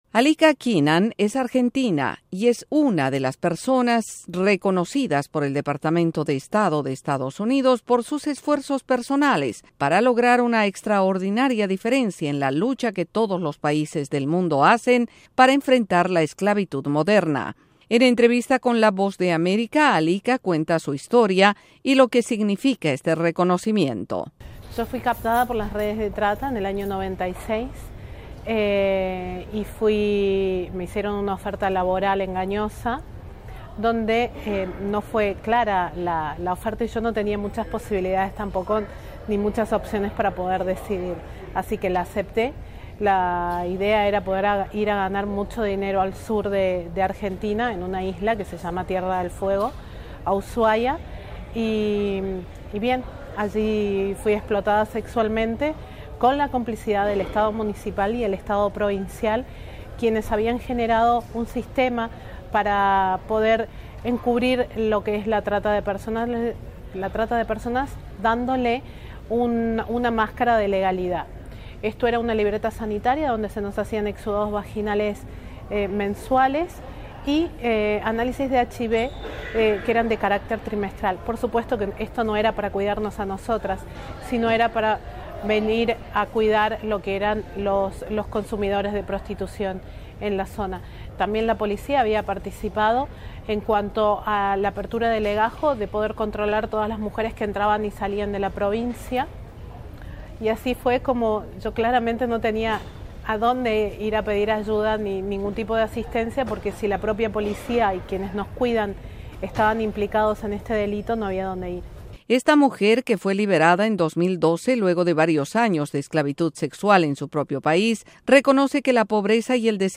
En entrevista con la Voz de...